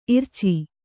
♪ irci